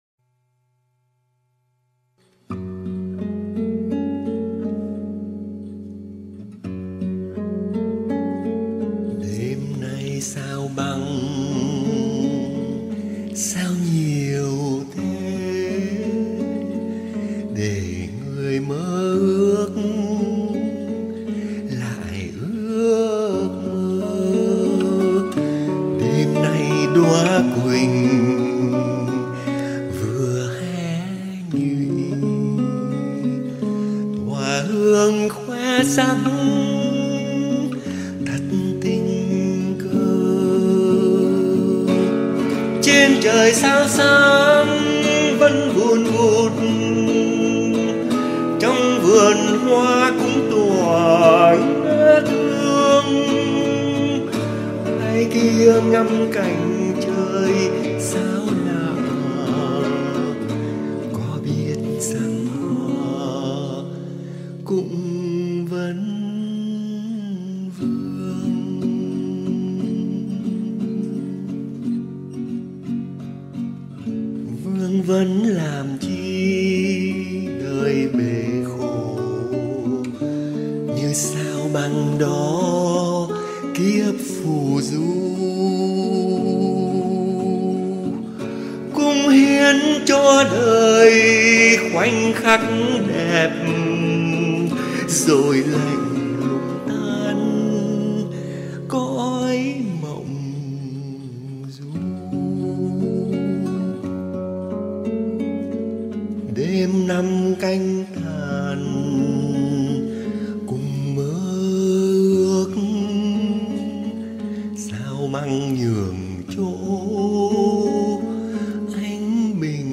đàn và hát